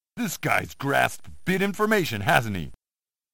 Seriously, I have no idea what's being said here.